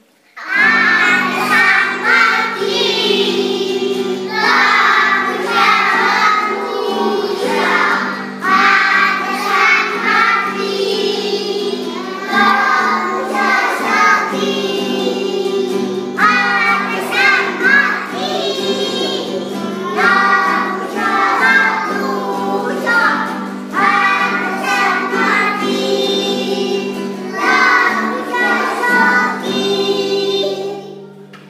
MÚSICA A E. INFANTIL
Els nens i nenes d’educació infantil canten tan i tan bé que els hem gravat perquè els pogueu escoltar des de casa: